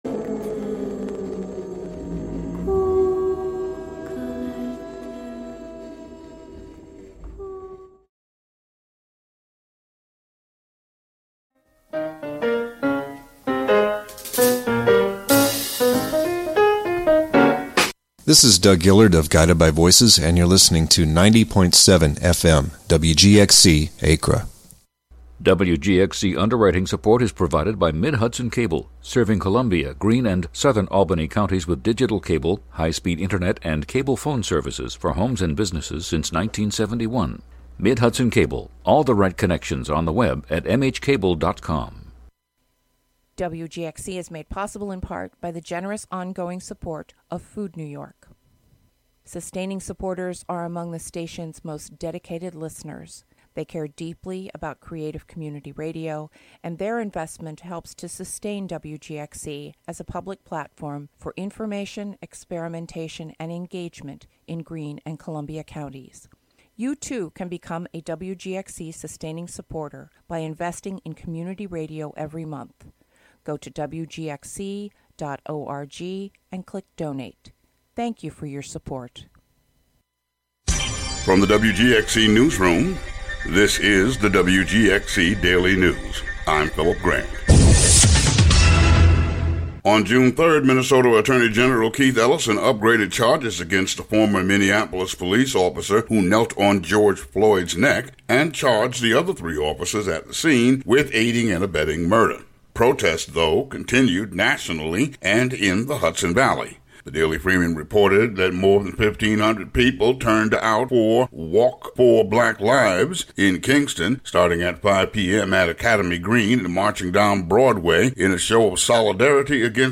In this broadcast, American author and social critic, James Howard Kunstler will be the guest.